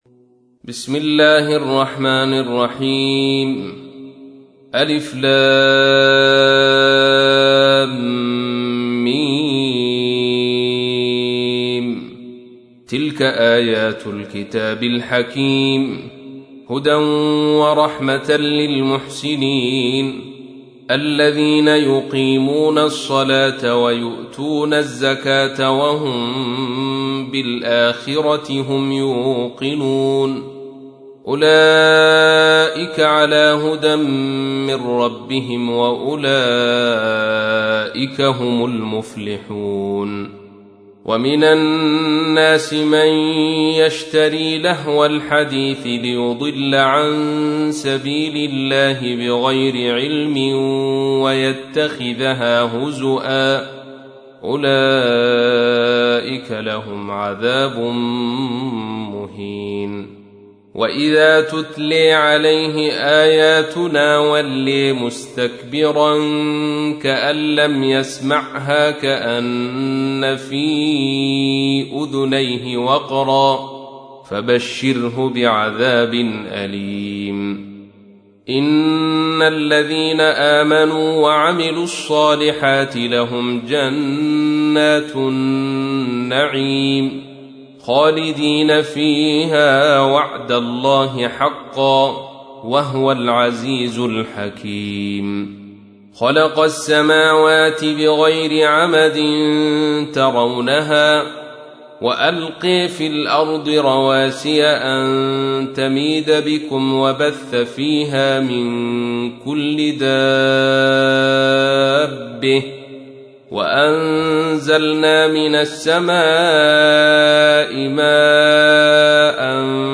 تحميل : 31. سورة لقمان / القارئ عبد الرشيد صوفي / القرآن الكريم / موقع يا حسين